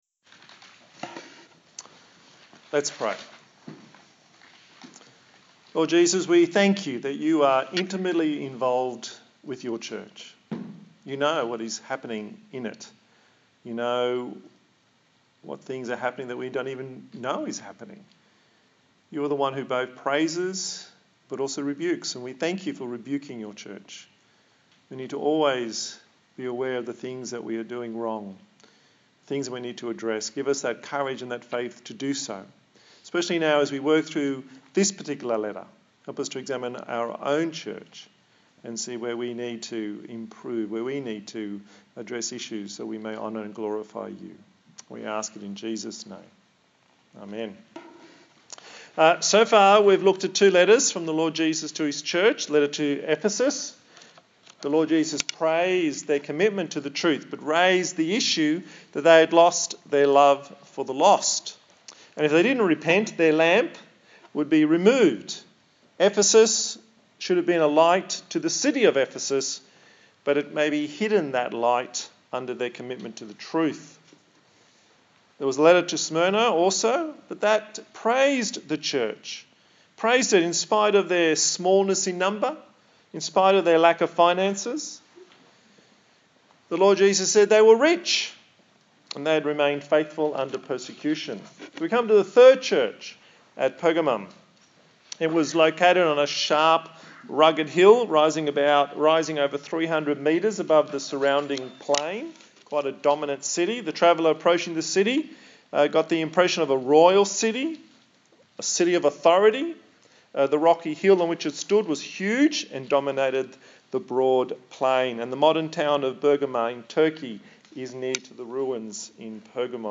A sermon in the series on the book of Revelation